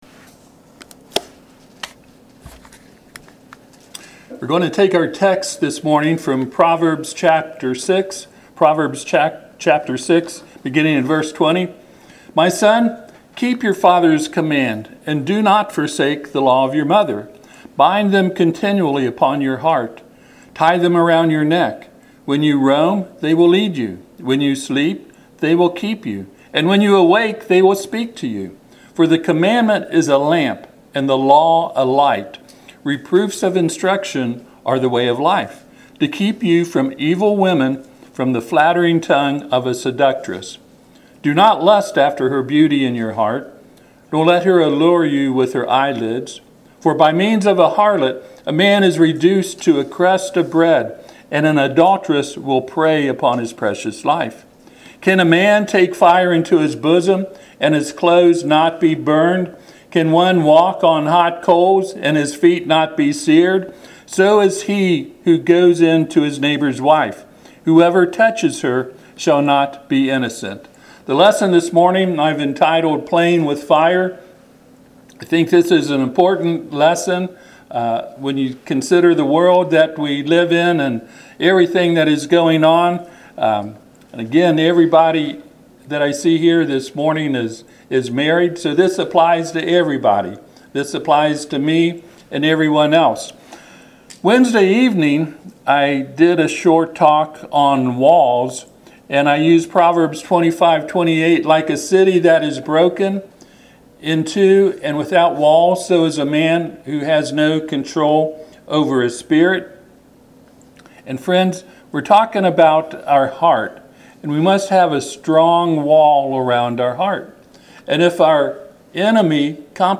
Passage: Proverbs 6:20-29 Service Type: Sunday AM